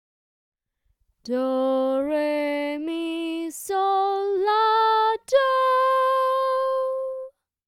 Michael: Solfa Scale
Ex-1-solfa-scale.mp3